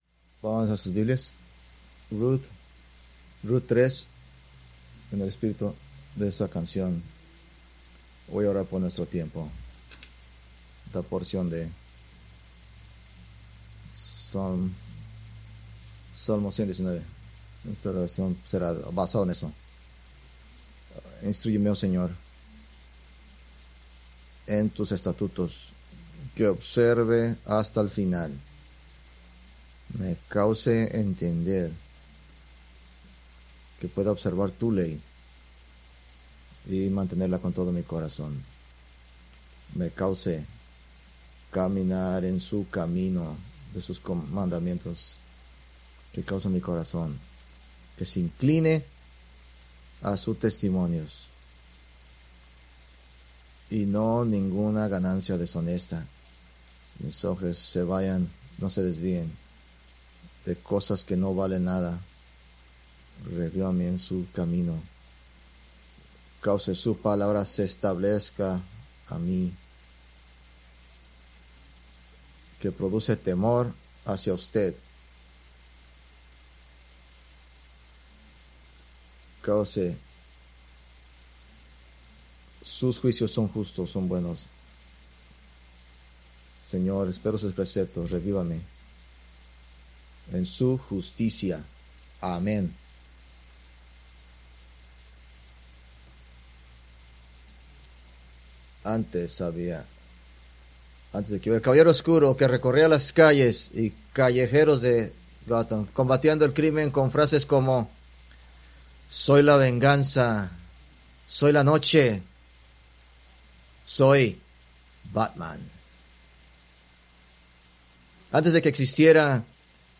Preached May 25, 2025 from Rut 3-4